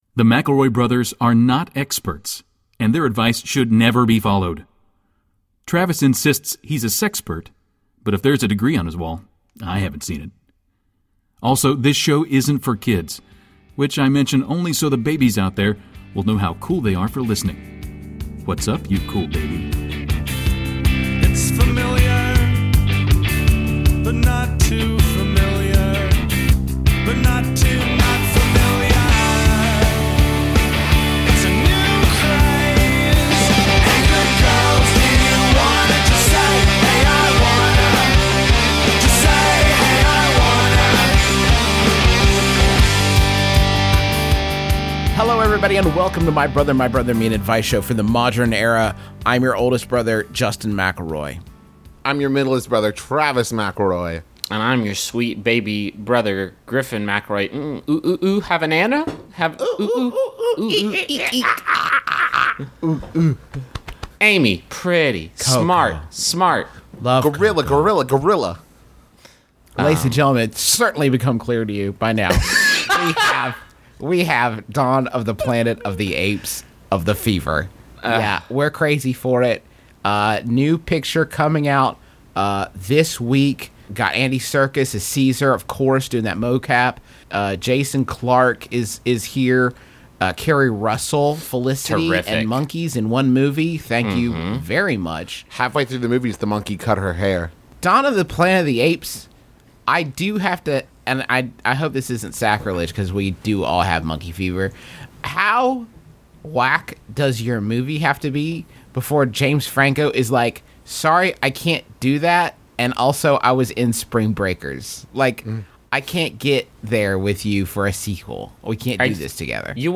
Mbmbam, Mcelroy Brothers, Advice, Justin Mcelroy, Travis Mcelroy, Comedy Advice, Mcelroy, Griffin Mcelroy, Comedy